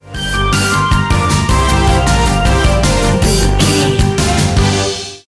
Aeolian/Minor
Fast
anxious
aggressive
driving
energetic
frantic
intense
bass guitar
synthesiser
percussion
electric piano